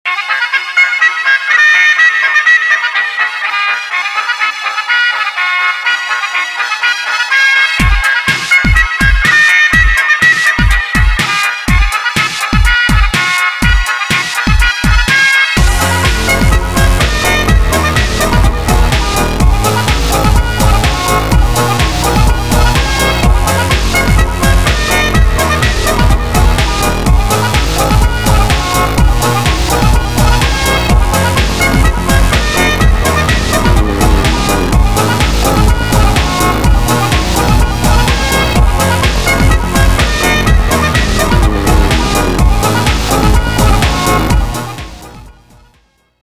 громкие
веселые
без слов
electro
бодрые
Энергичный рингтон на входящий звонок